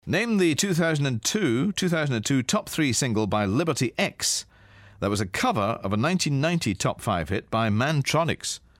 Here's Ken with today's hard PopMaster question - this is a real toughie!